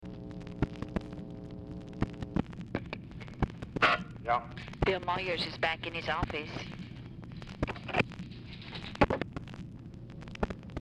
Telephone conversation
Format Dictation belt
Location Of Speaker 1 Oval Office or unknown location